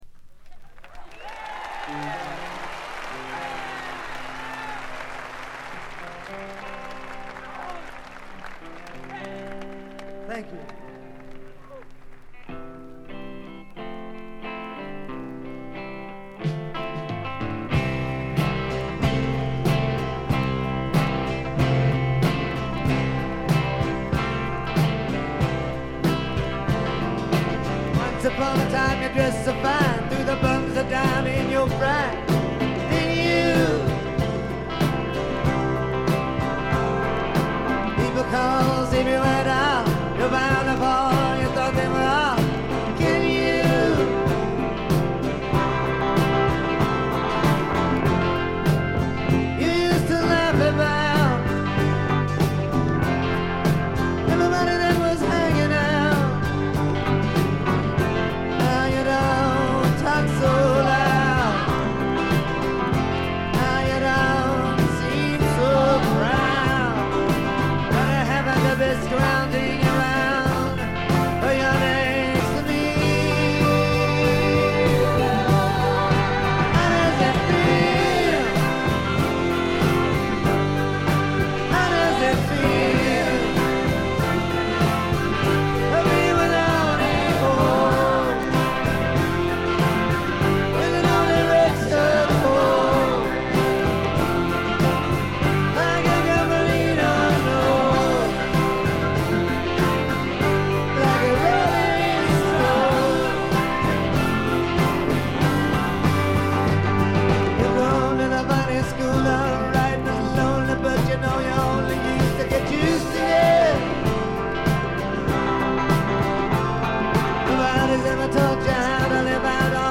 部分試聴ですがほとんどノイズ感無し。
試聴曲は現品からの取り込み音源です。